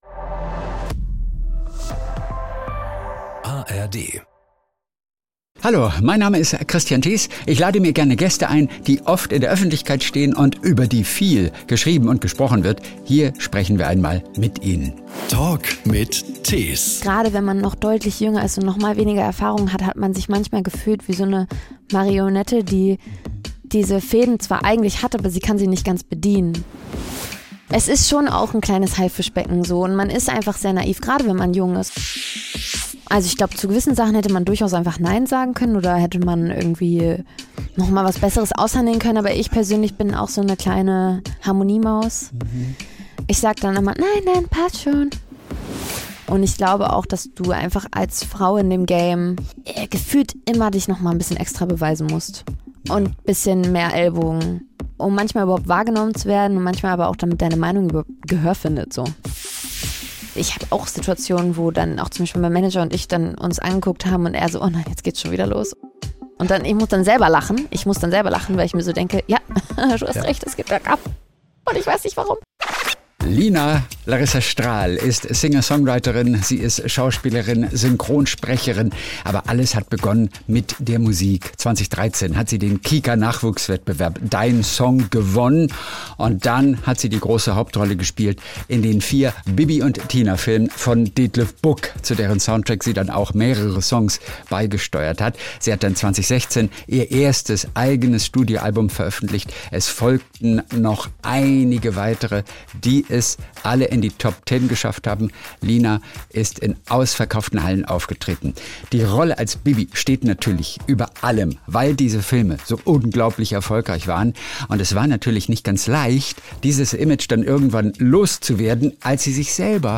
In diesem Podcast erzählt sie sehr offen, was sie besonders belastet hat und wie mit ihr umgegangen wurde – bis sie schließlich krank wurde. Sie spricht auch über eigene Schwächen, etwa ihre Harmoniesucht, die ihr nicht immer geholfen hat. Ein erfrischendes und liebenswertes Gespräch mit einer, die sich wieder wohl in ihrer Haut fühlt.